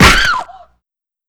body_medium_impact_hard5.wav